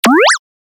جلوه های صوتی
برچسب: دانلود آهنگ های افکت صوتی اشیاء دانلود آلبوم صدای کلیک موس از افکت صوتی اشیاء